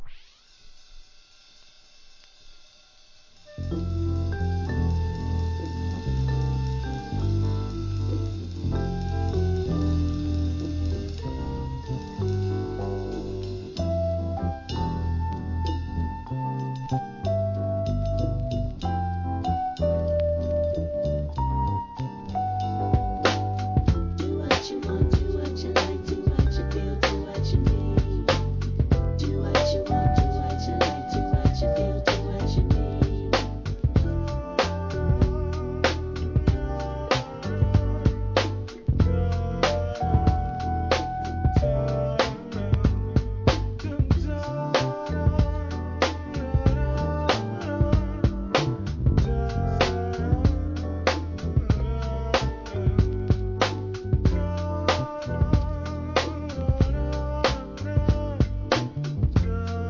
HIP HOP/REGGARE/SOUL/FUNK/HOUSE/
REMIX PROJECT!!